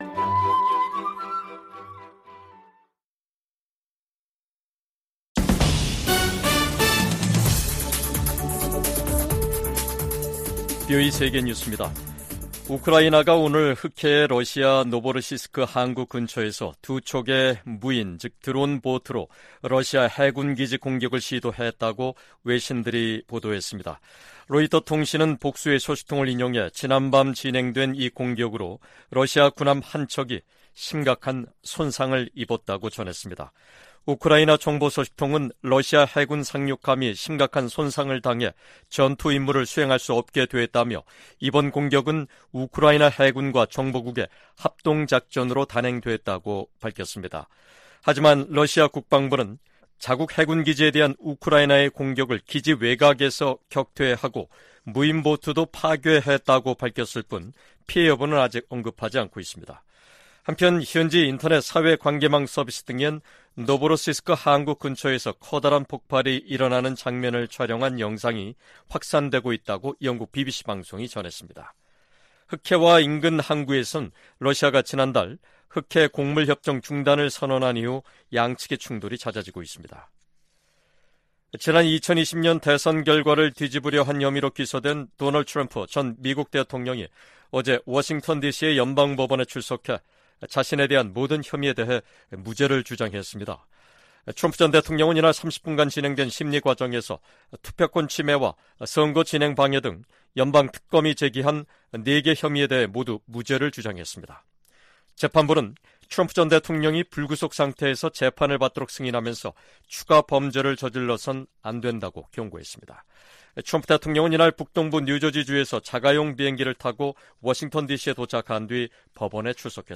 VOA 한국어 간판 뉴스 프로그램 '뉴스 투데이', 2023년 8월 4일 2부 방송입니다. 오는 18일 미한일 정상회의에서 북한의 미사일 방어 등 3국 안보 협력을 강화하는 방안이 논의될 것이라고 한국 국가안보실장이 밝혔습니다. 러시아 국방장관의 최근 평양 방문은 군사장비를 계속 획득하기 위한 것이라고 백악관 고위관리가 지적했습니다. 토니 블링컨 미 국무장관은 북한이 월북 미군의 행방과 안위등에 관해 답변을 하지 않았다고 말했습니다.